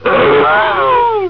Blanka-growl.wav